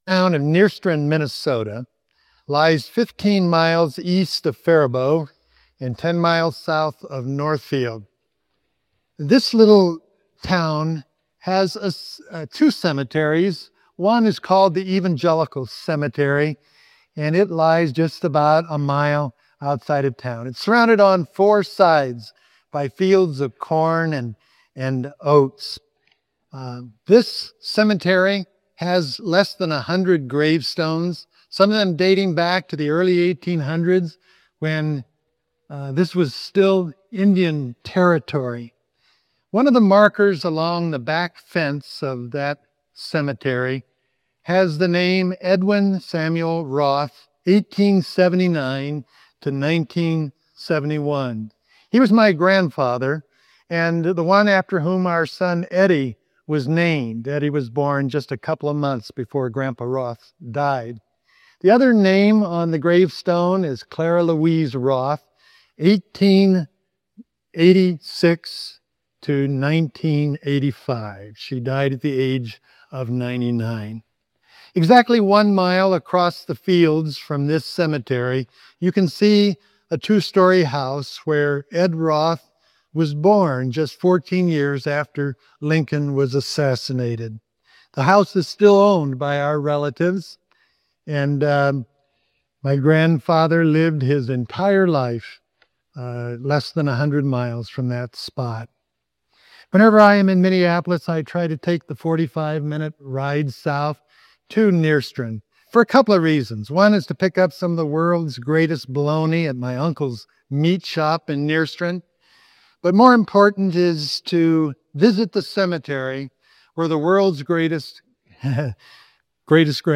Note : This sermon was an extended Communion meditation.